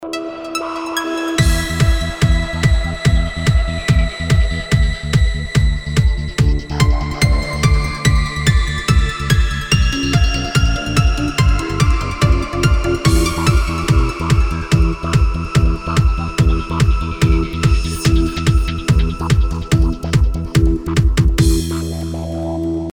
زنگ موبایل ورزشی و راز آلود بی کلام